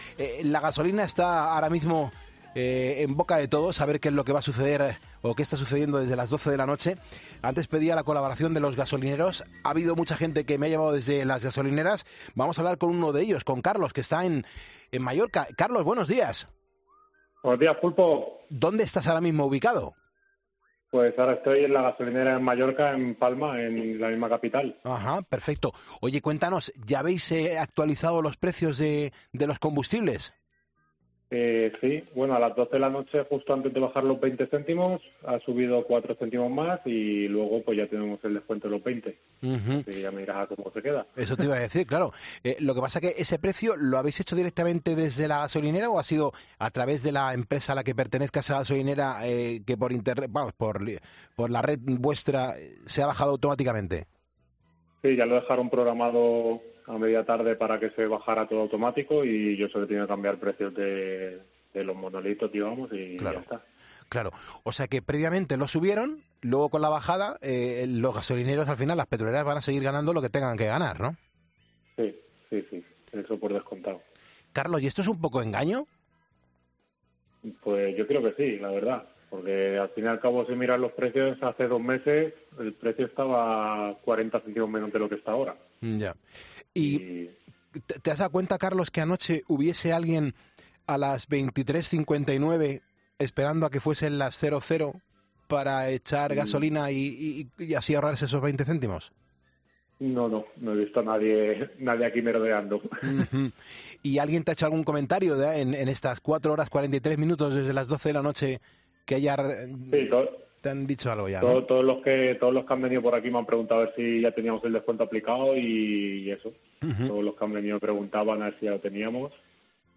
En 'Poniendo las calles' hemos hablado con el trabajador de una gasolinera para conocer, de primera mano, cómo se están viviendo estas primeras horas del descuento en el carburante